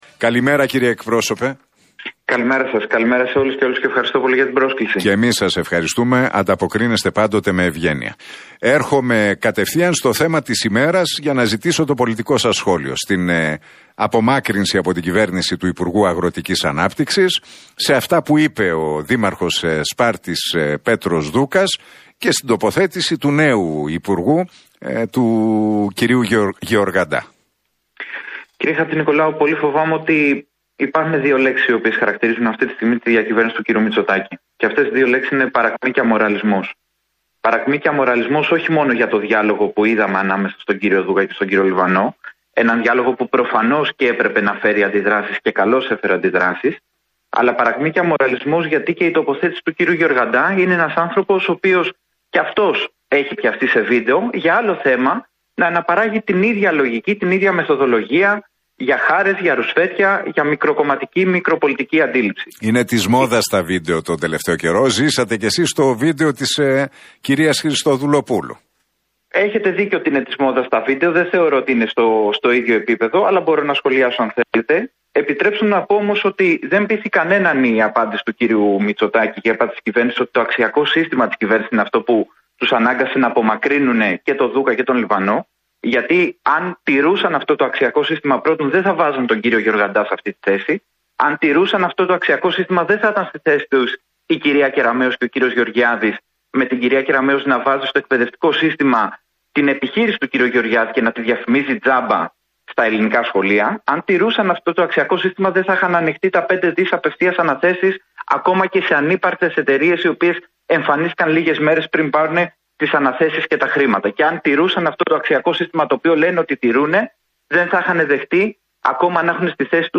Ηλιόπουλος στον Realfm 97,8: Οι δηλώσεις Χριστοδουλοπούλου διαστρεβλώθηκαν σε μεγάλο βαθμό, ειδικά για τη δικαιοσύνη